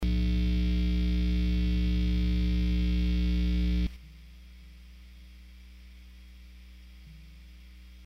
The same chord and volume setting was used for each guitar.
Listen to the difference between the first 5 seconds and the last 5 seconds.
CLIP 1   Noise Eliminated!!